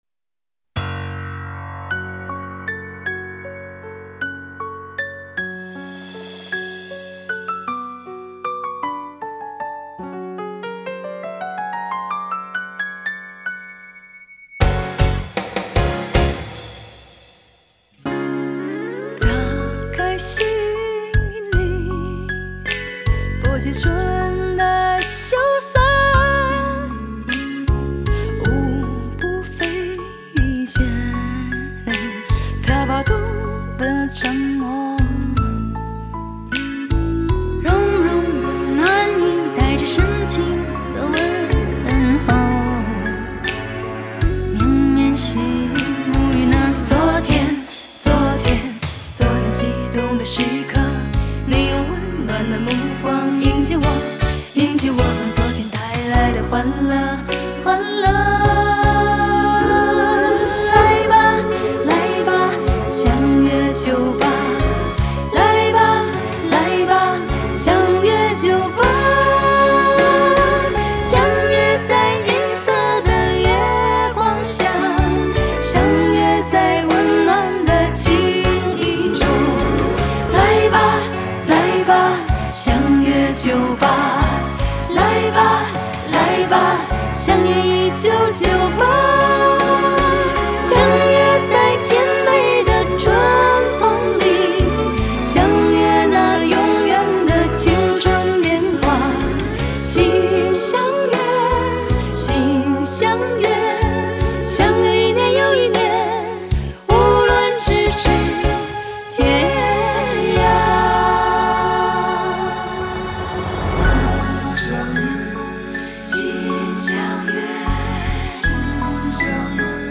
通过春晚唱响大江南北